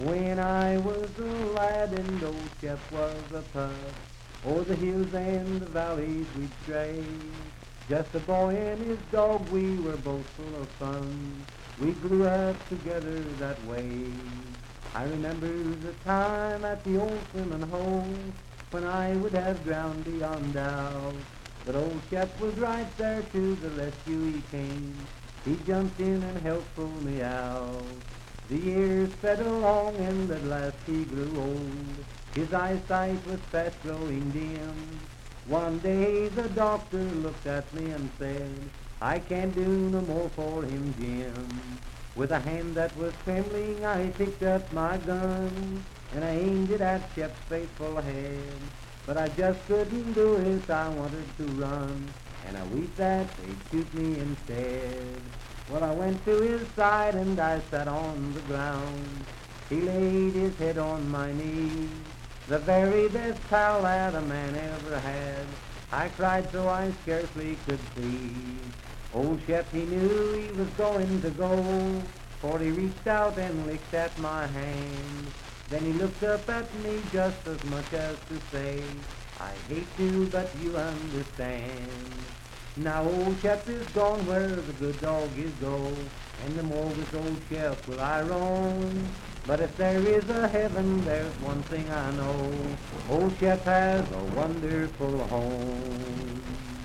Unaccompanied vocal music
Verse-refrain 4(8).
Voice (sung)